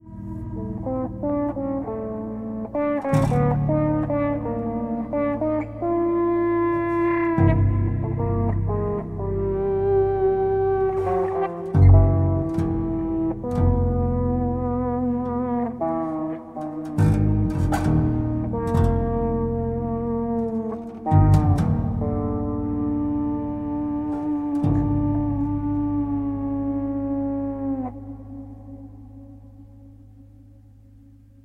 Electric guitar